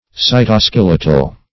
Search Result for " cytoskeletal" : The Collaborative International Dictionary of English v.0.48: cytoskeletal \cy`to*skel"e*tal\ (s[imac]`t[-o]*sk[e^]l"[e^]*tal), a. (Cell Biology) Of or pertaining to the cytoskeleton; as, cytoskeletal microtubules.
cytoskeletal.mp3